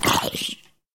zombiehurt1.mp3